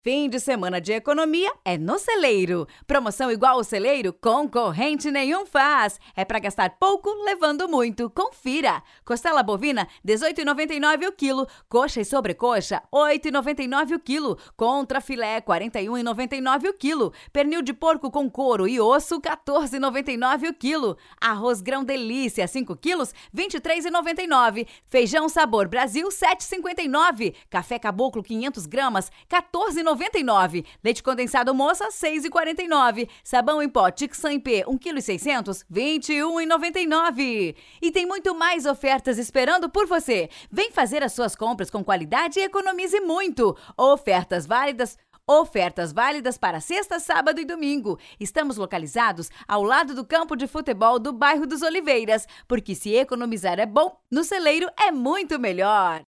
OFF SUPERMERCADO CELEIRO: